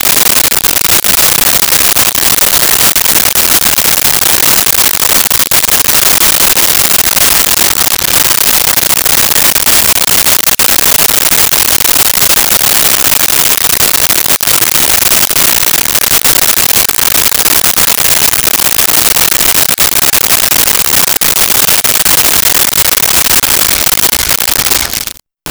Cooking Pan Sizzle 02
Cooking Pan Sizzle 02.wav